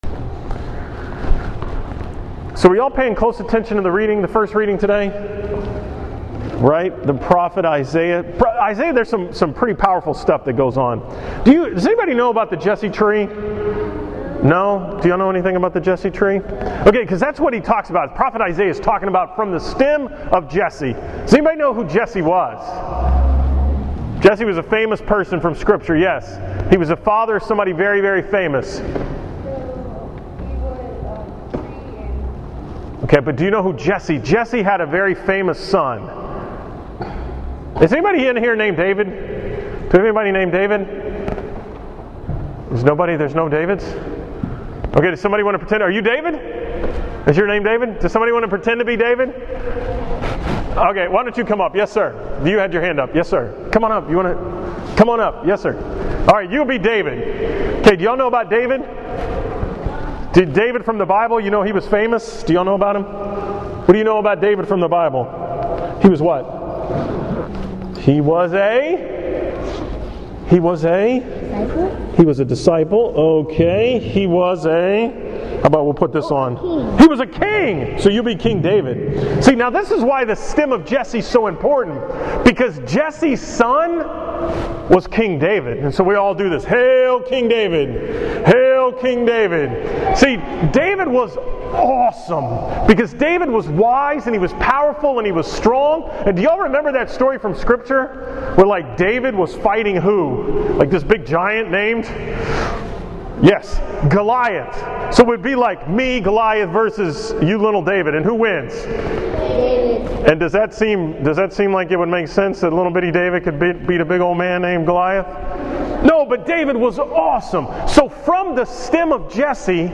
From the school Mass at Our Lady of Guadalupe on Tuesday, December 1st, 2015.